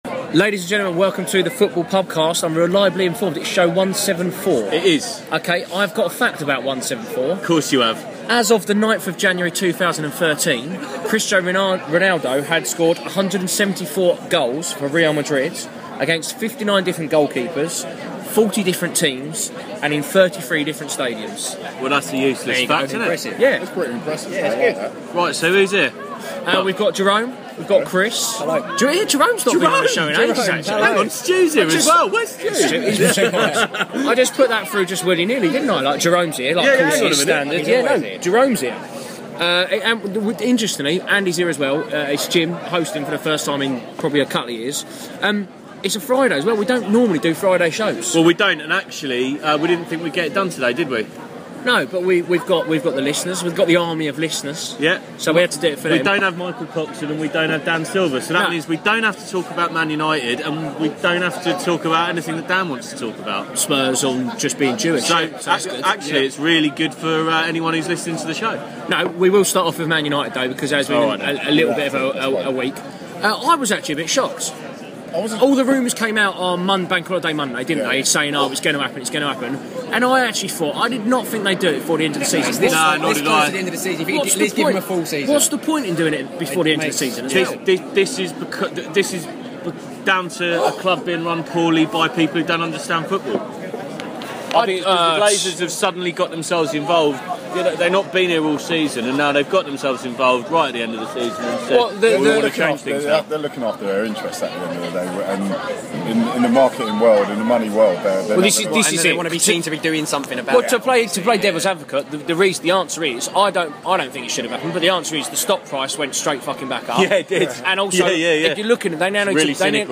Its Friday, it was generic, it can only be a last minute Pubcast!